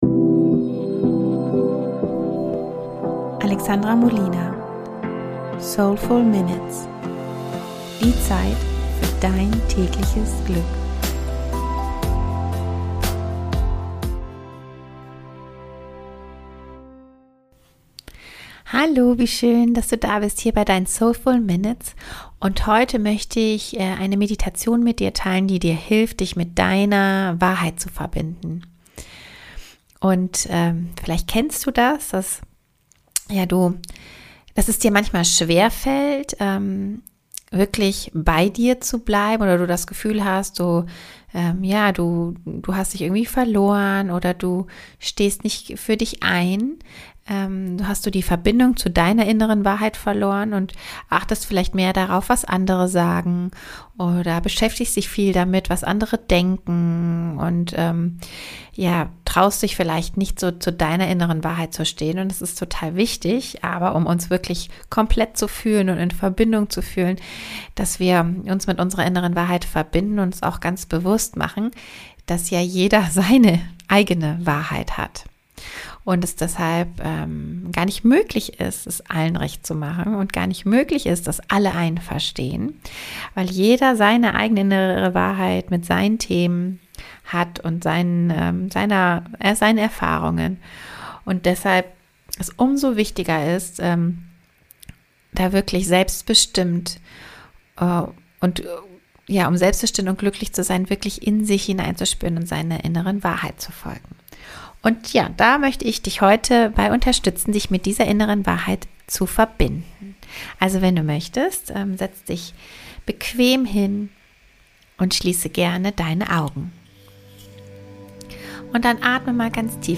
Meditation für innere Wahrheit und Klarheit ~ Soulful Minutes - Zeit für dich Podcast